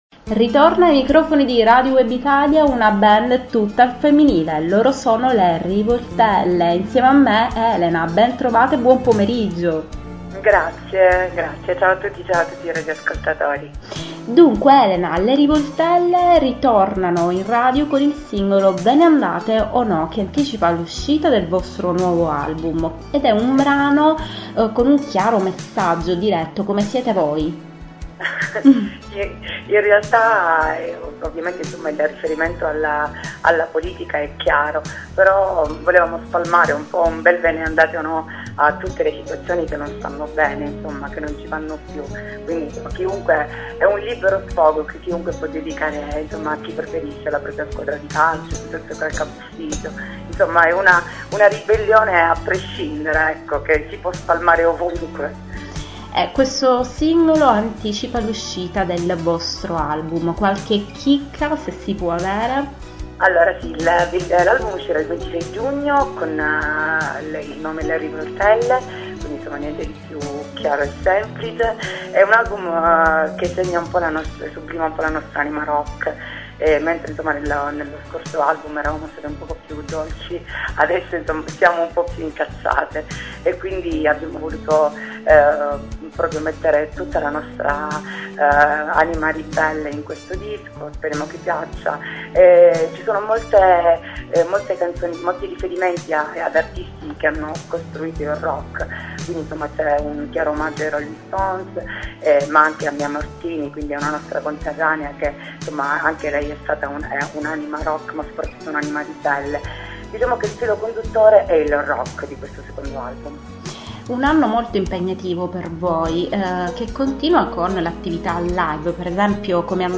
Le Rivoltelle: intervista su Radio Web Italia
rivoltelle-intervista-06.mp3